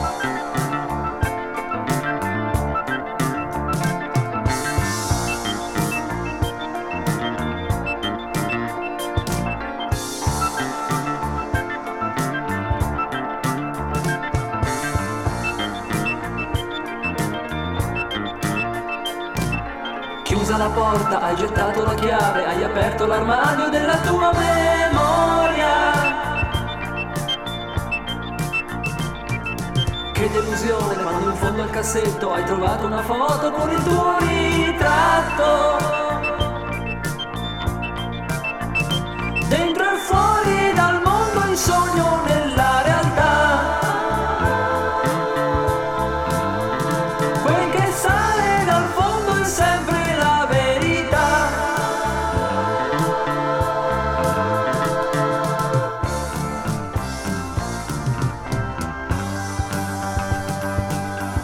オルガンをフィーチャーした